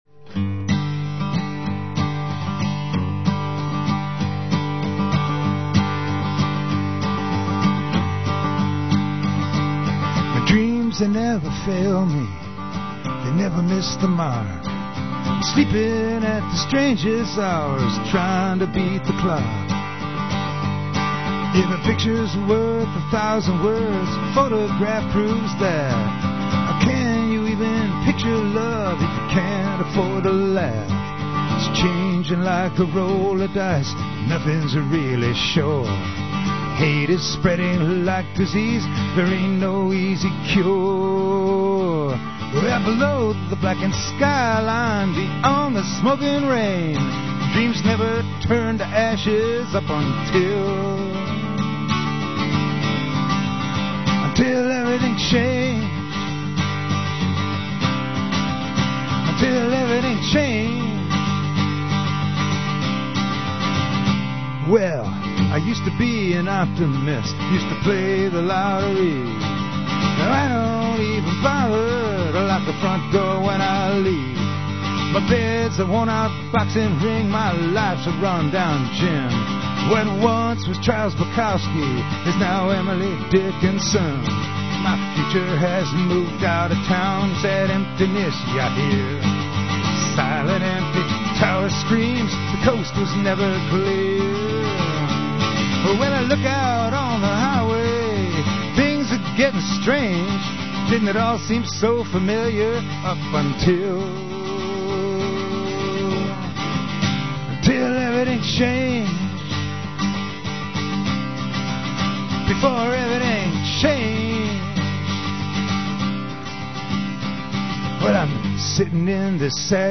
live songs (from radio)
mono